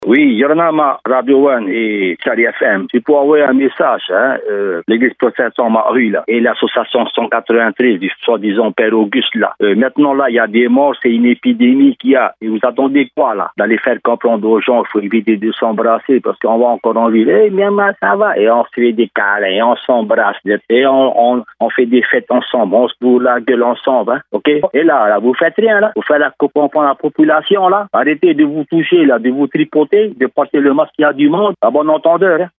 Répondeur de 6:30, le 15/10/2020